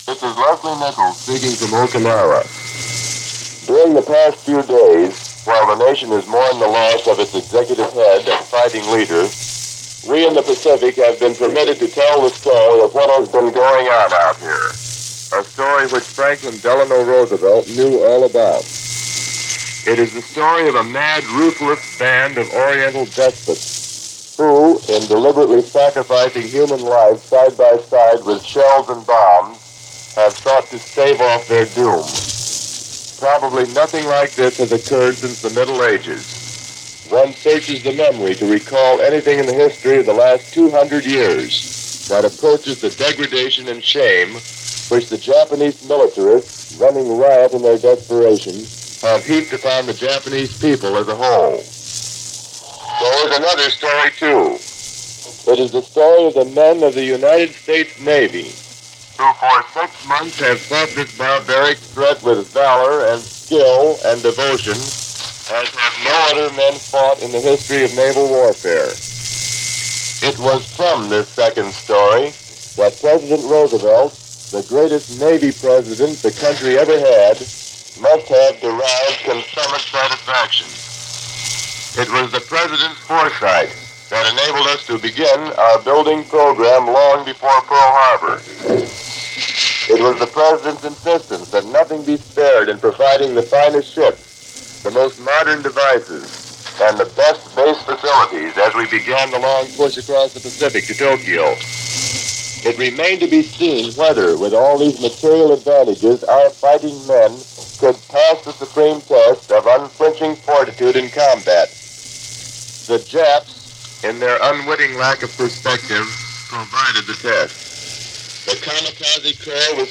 News for this day in 1945 from Mutual Broadcasting.